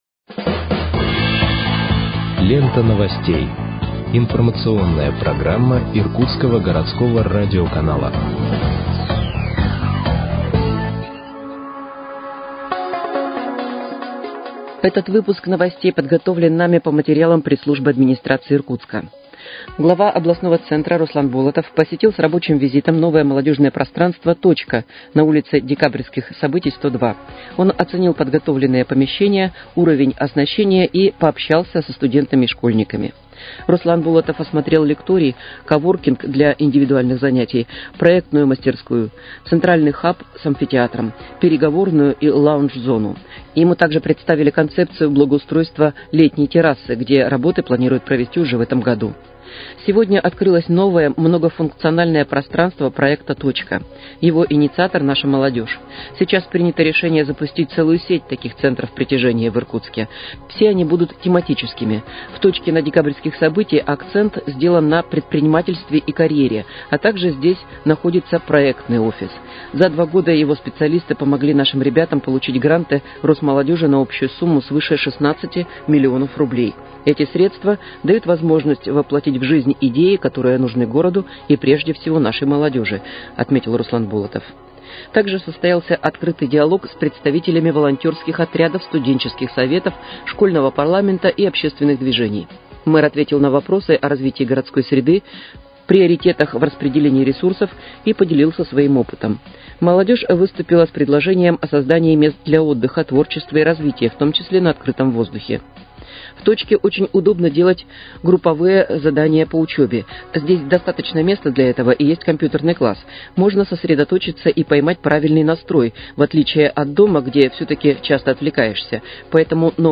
Выпуск новостей в подкастах газеты «Иркутск» от 6.02.2026 № 2